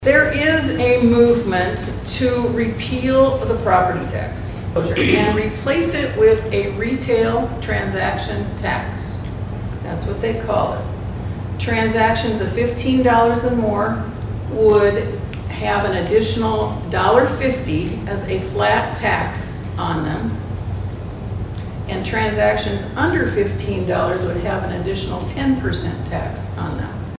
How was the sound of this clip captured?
ABERDEEN, S.D.(HubCityRadio)- The Aberdeen Chamber of Commerce’s Chamber Connections Series continue Thursday at the K.O.Lee Public Library.